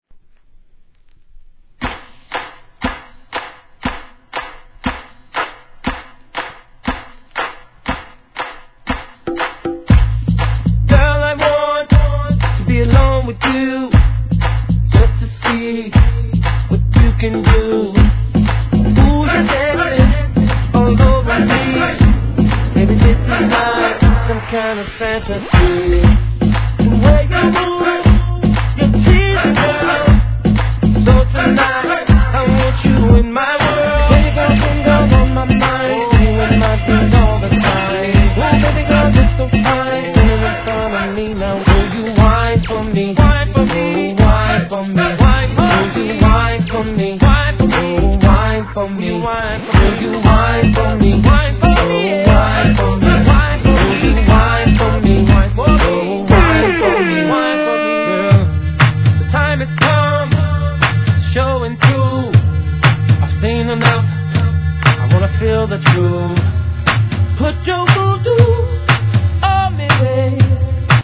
HIP HOP/R&B
REGGAEとHIP HOPをクロス・オーヴァーするDJの方には重宝する企画でしょう!!